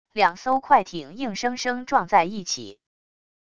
两艘快艇硬生生撞在一起wav音频